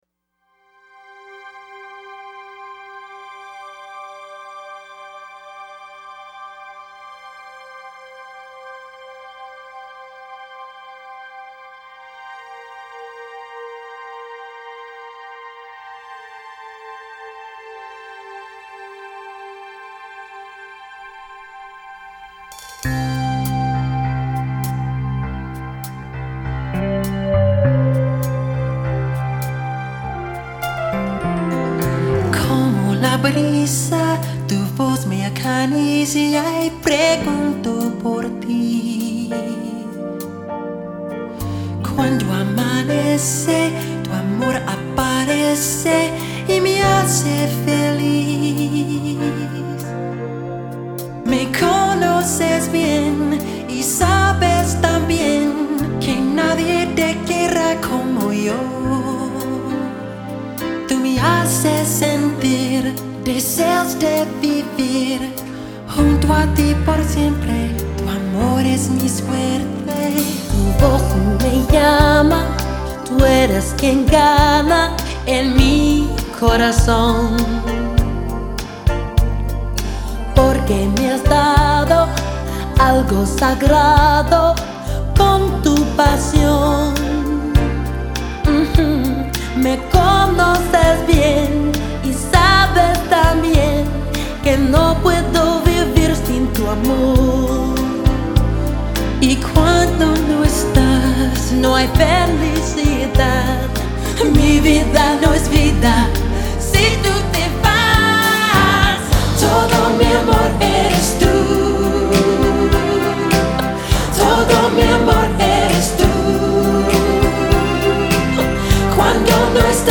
Жанр: Pop-Rock, Soul Pop, downtempo, Funk